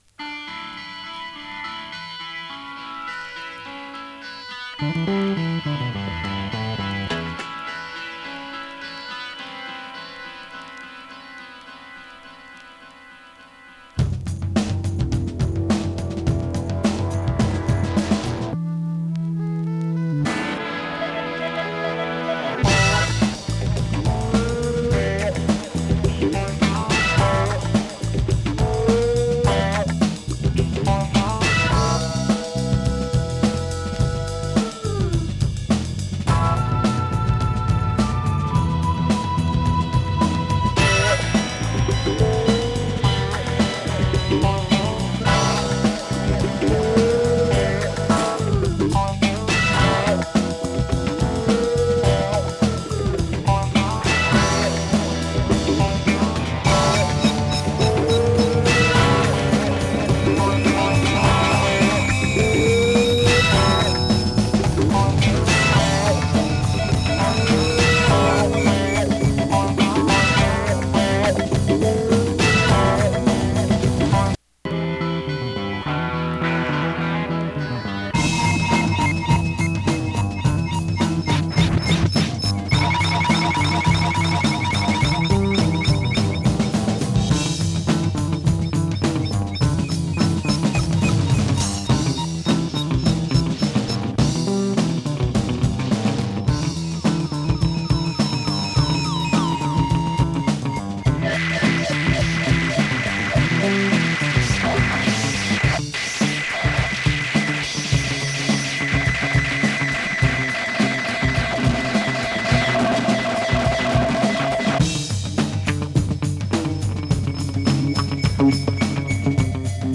[3track 7inch]＊音の薄い部分で時おり軽いチリパチ・ノイズ。